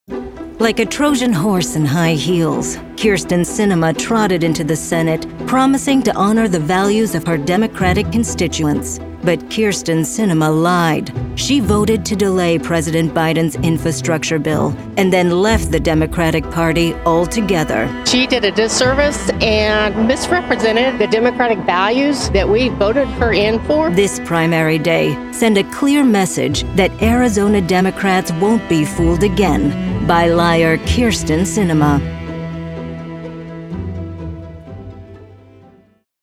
Political Voice Demos
Professional Female Political Voiceover
• Home Studio